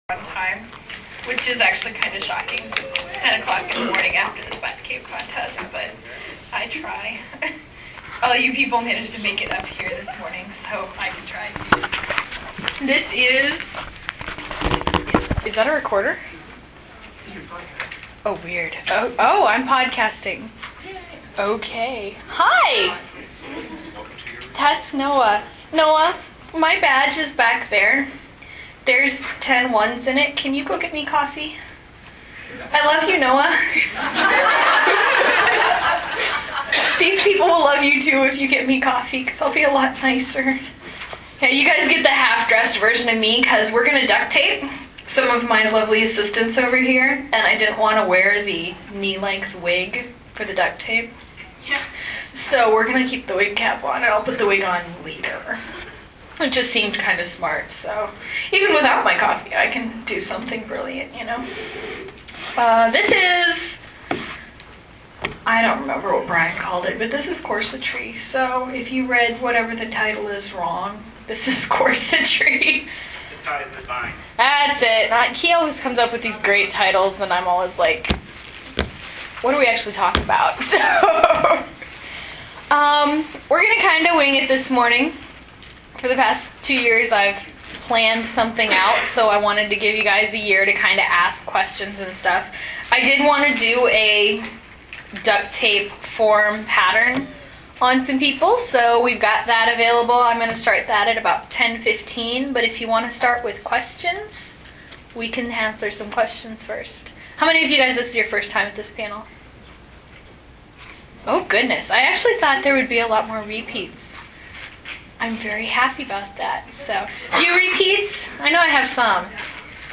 This presentation was made through the costuming track at DragonCon 2006.